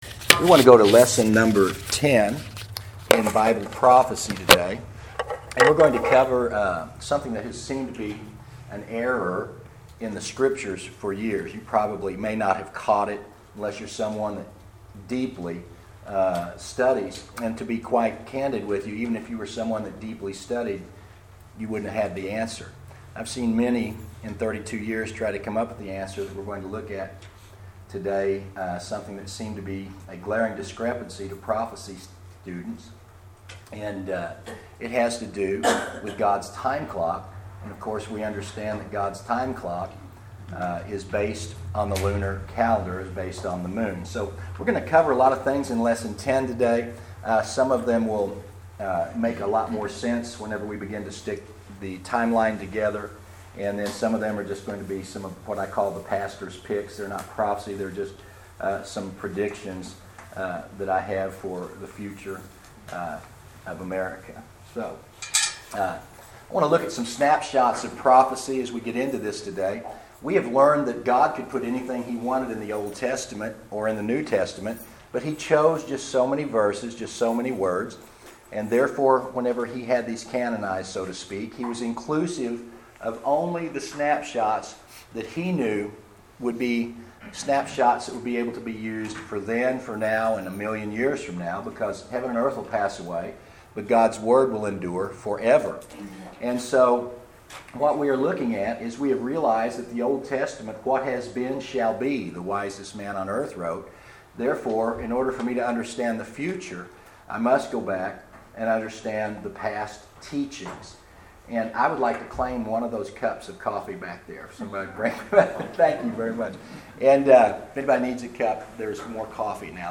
Bible Prophecy: Lesson 10